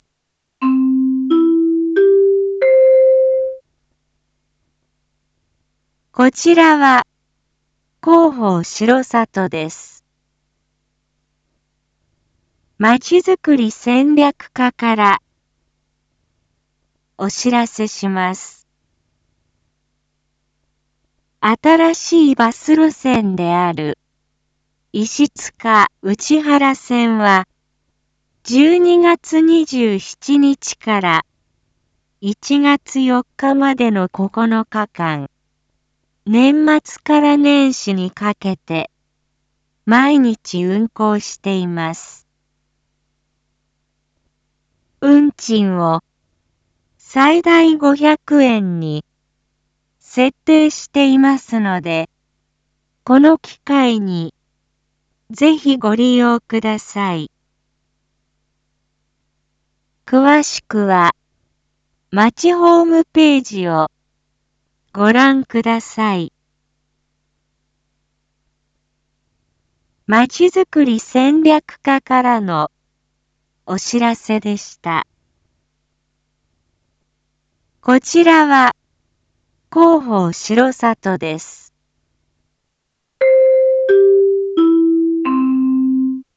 一般放送情報
Back Home 一般放送情報 音声放送 再生 一般放送情報 登録日時：2025-12-28 19:01:28 タイトル：石塚・内原線の年末年始の運行について② インフォメーション：こちらは広報しろさとです。